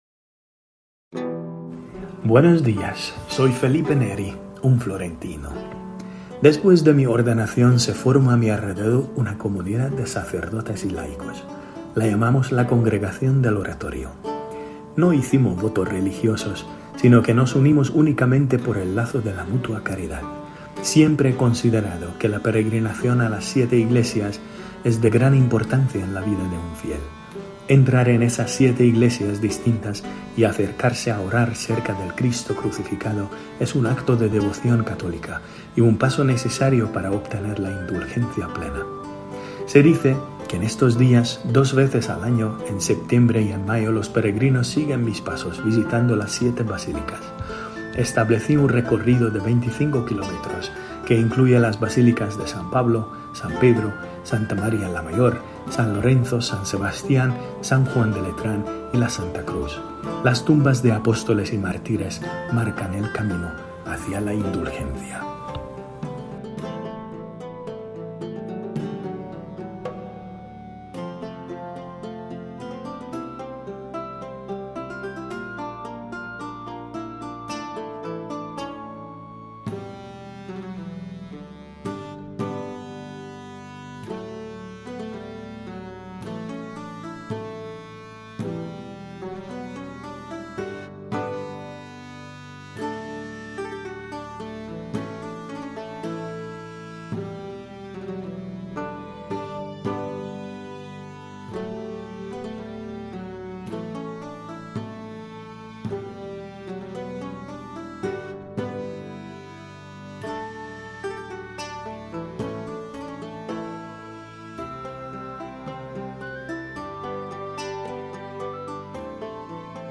Felipe-Neri-with-music.mp3